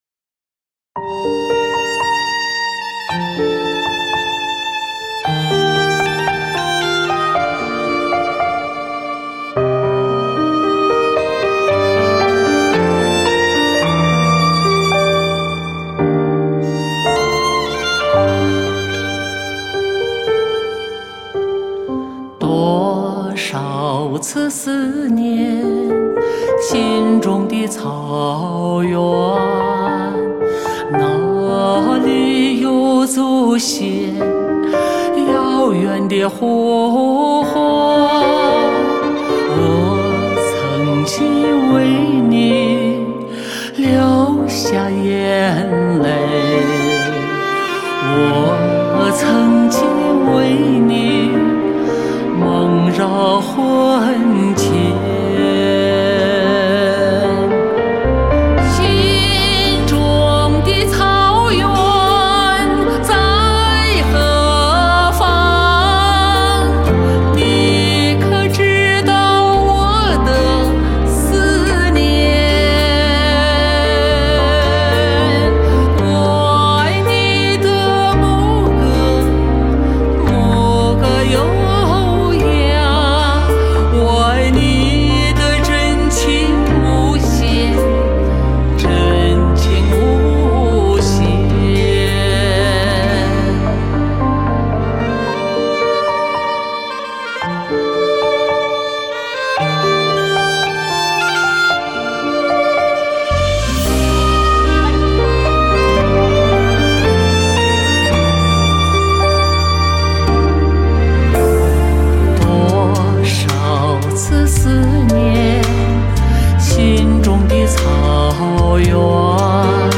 中音 温暖和甜蜜 却为贴心与自然 朴素无华
集合顶级的女中音歌唱家，奉献他们最有代表性的曲目
独具魅力的艺术演唱，让你领略中音的醇厚之美